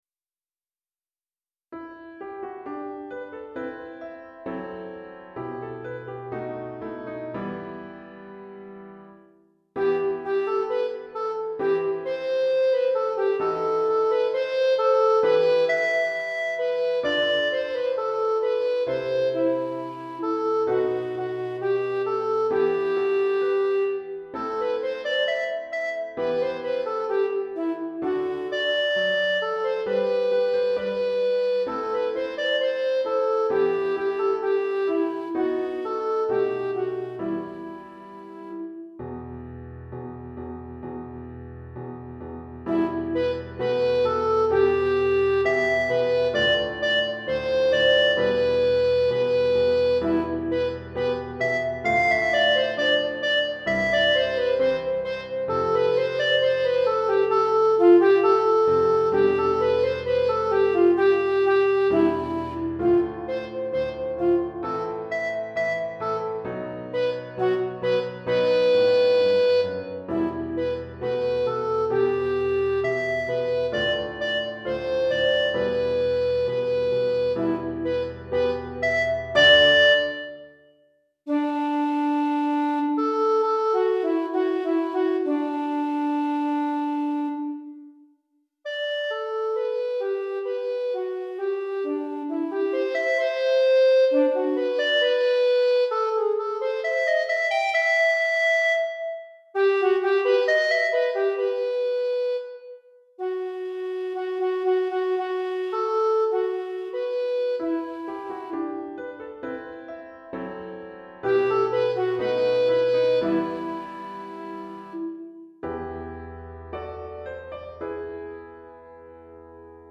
Pour flûte à bec et piano DEGRE FIN DE CYCLE 1